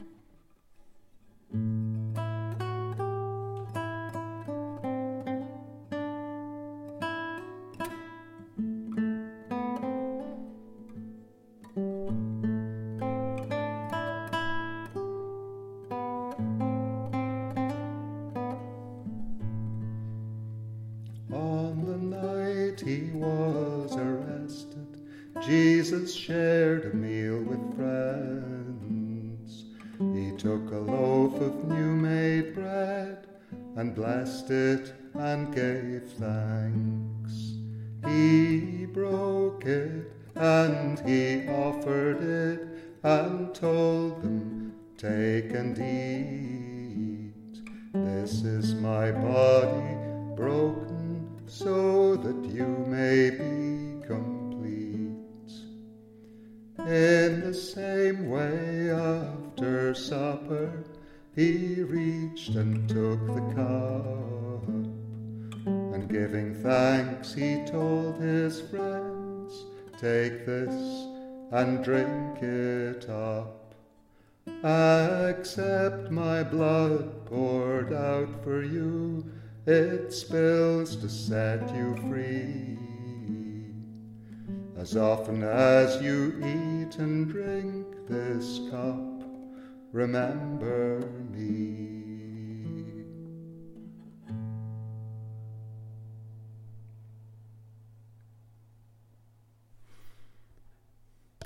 The last supper as it is told in Corinthians, to one of the great English folk tunes, Brigg Fair. Percy Grainger made an elaborate setting of it: for me, the bare simplicity is the essence.